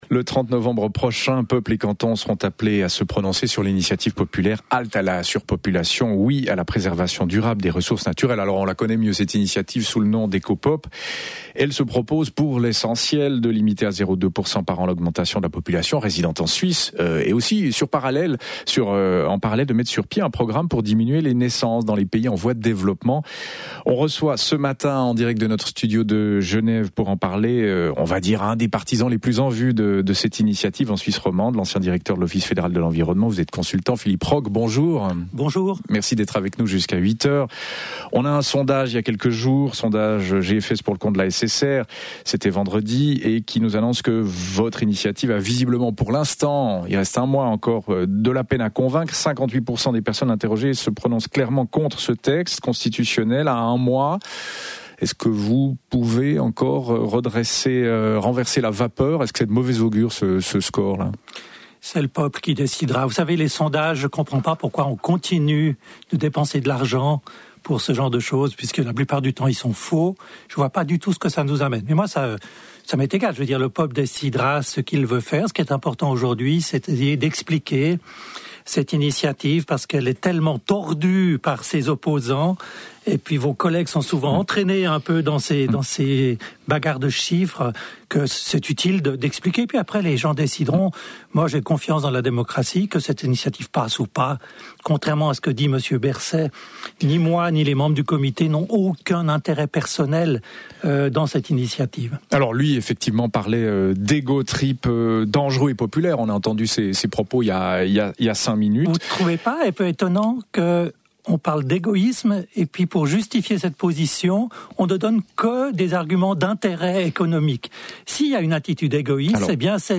Ecopop: débat entre Fernand Cuche et Anne-Marie Rey
Fernand Cuche, ancien conseiller d'Etat neuchâtelois, s'oppose à l'initiative; Anne-Marie Rey, socialiste, ancienne députée au Grand Conseil bernois et militante de la cause féminine, y est favorable. Ils répondent aux questions des auditeurs :